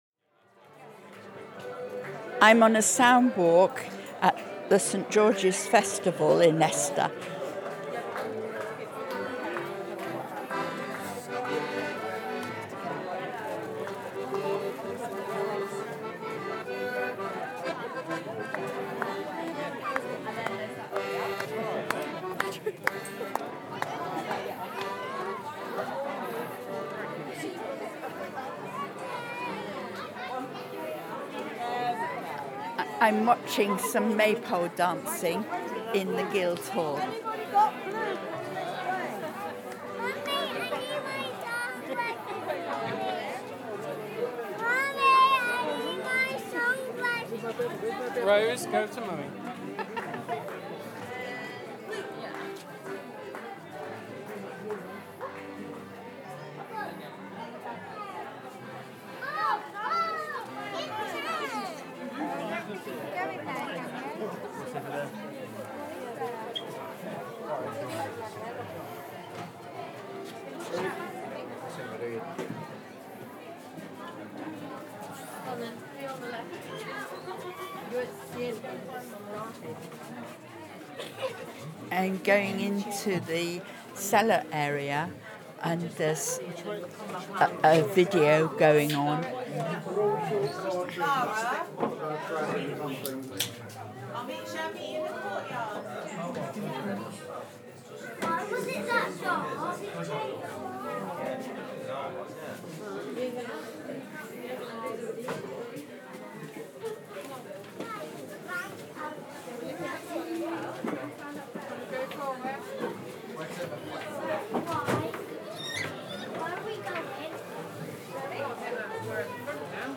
Celebrating St George’s Day – A Day at the Festival in Leicester Soundwalk
Then I took up the offer to go around the festival recording the sounds that I heard.
My sound walk began in the courtyard of the Guildhall with a visit to the Maypole dancing area. Moving on I came across a Scout band warming up their instruments, I listened for a while and then moved on to watch a Viking coin making workshop.